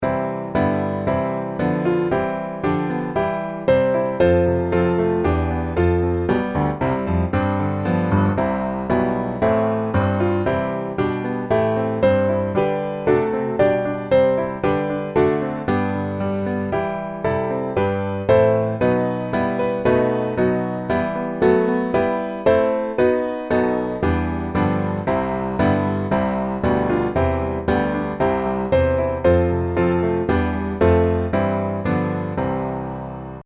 Piano Hymns
C Major